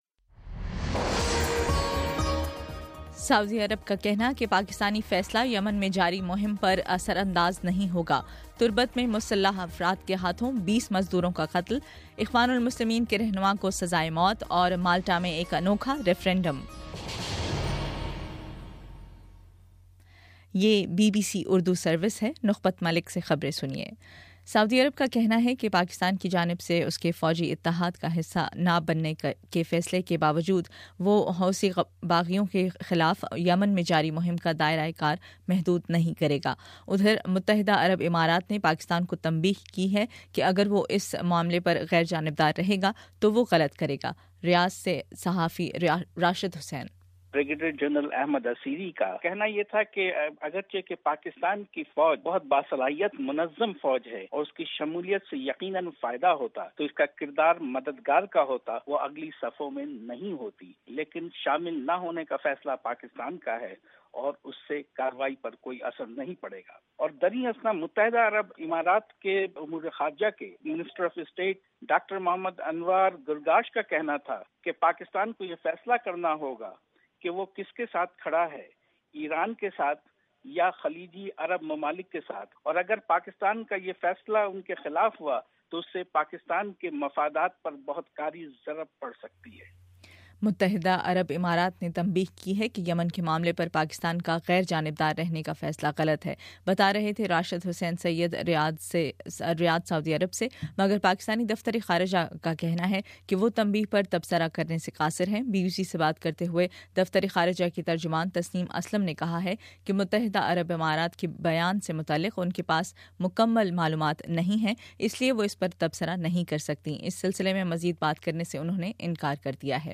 اپریل 11: شام چھ بجے کا نیوز بُلیٹن